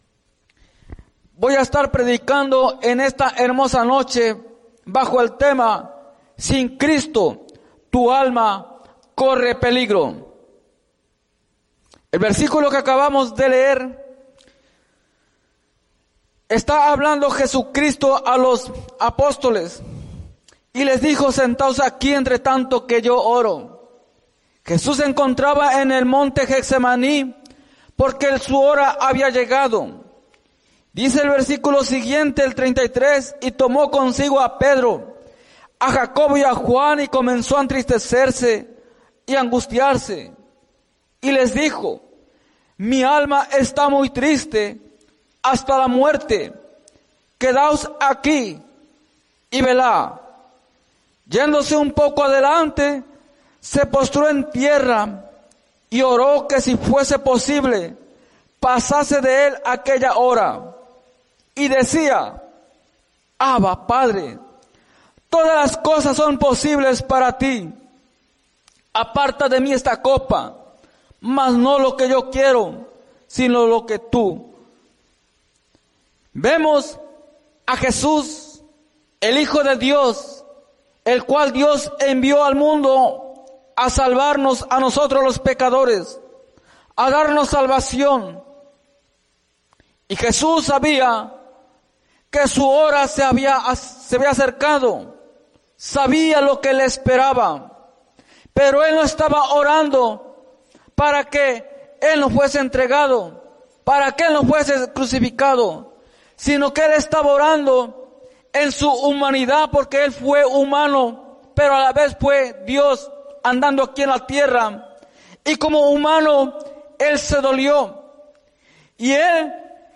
Mensaje
en la Iglesia Misión Evangélica en Norristown, PA